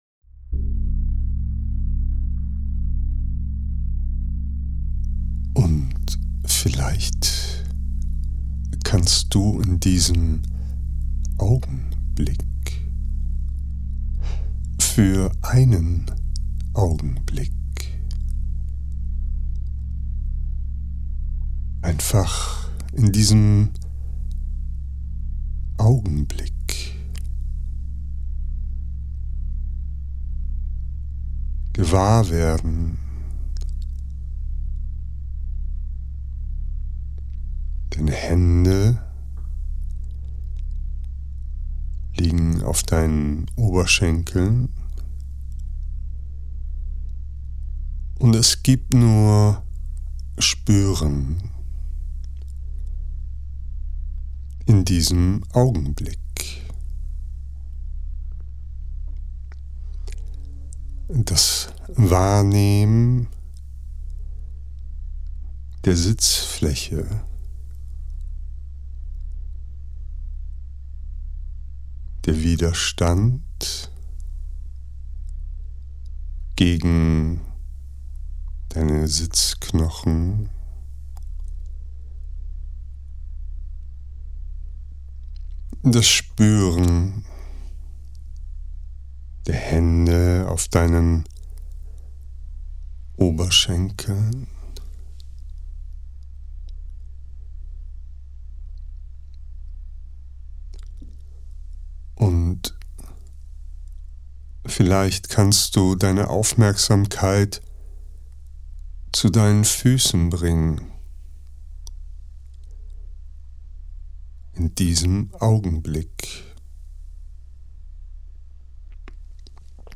Eine geführte Meditation, um die Fähigkeit zu erlangen Abstand von Gedanken und Gefühlen zu finden.